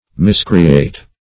Miscreate \Mis`cre*ate"\, a.